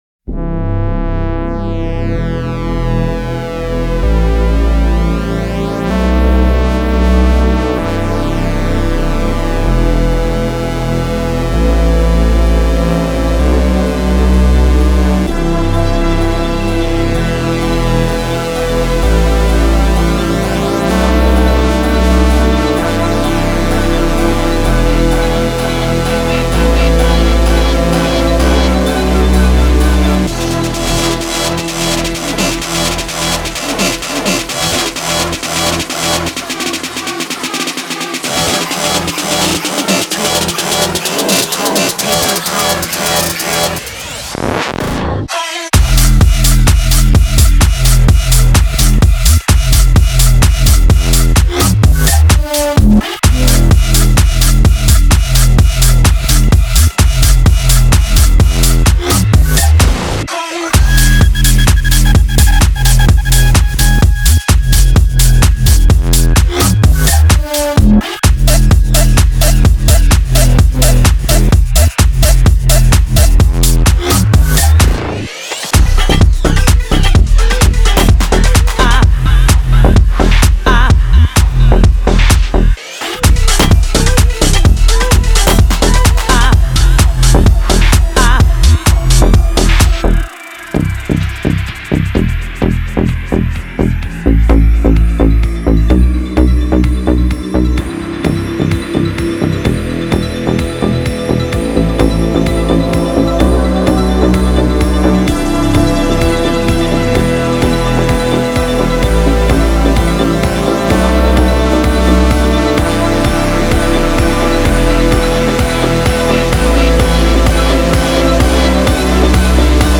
BPM128-128
Audio QualityPerfect (High Quality)
Bass House song for StepMania, ITGmania, Project Outfox
Full Length Song (not arcade length cut)